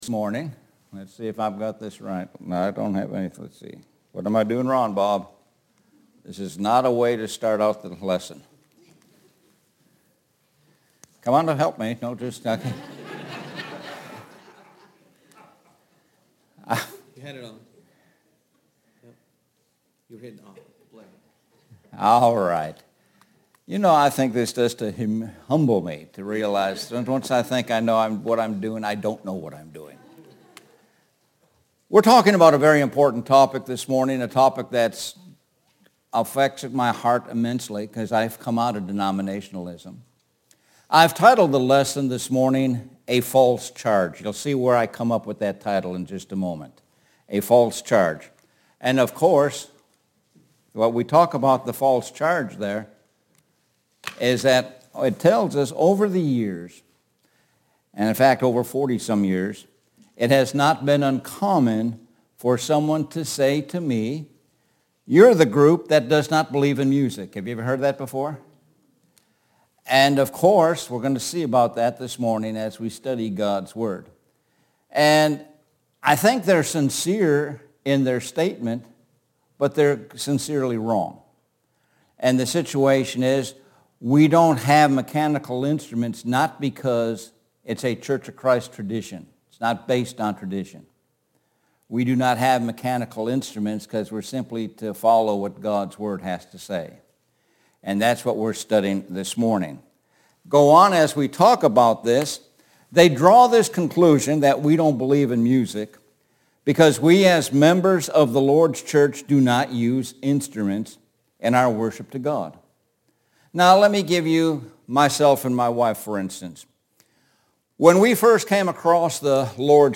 Sun AM Worship